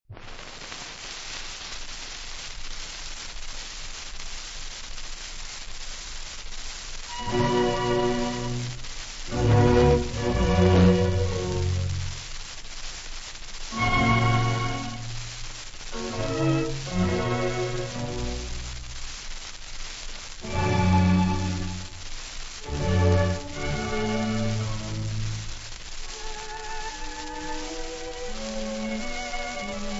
Grand concerto No. 12 in b minor / Handel ; Ernest Ansermet conducting The Decca String Orchestra
Contiene: 1st movement: Largo; 2n movement: Allegro; 3rd movement: Larghetto e piano; 4th movement: Largo-Allegro
• Ansermet, Ernest [direttore d'orchestra]
• The Decca String Orchestra [interprete]
• registrazione sonora di musica